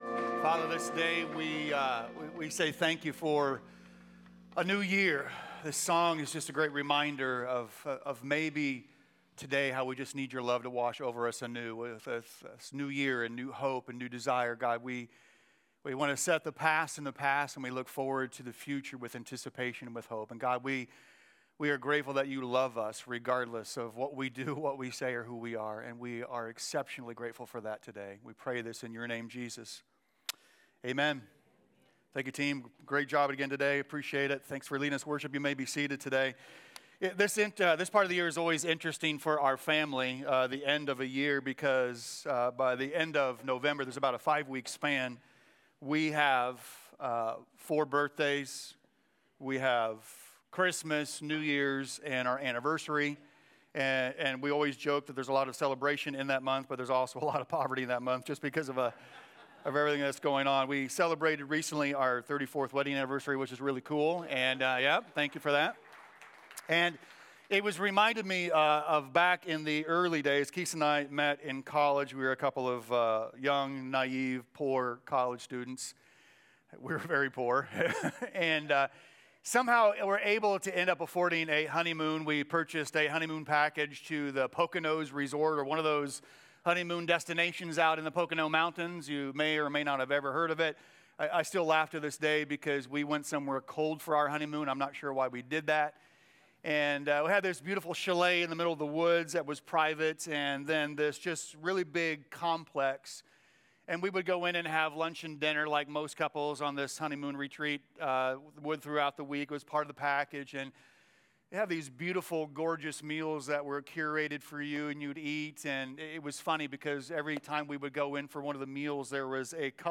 The weekly message from Commonway Church located in Muncie, IN.